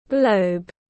Quả địa cầu tiếng anh gọi là globe, phiên âm tiếng anh đọc là /ɡləʊb/
Globe /ɡləʊb/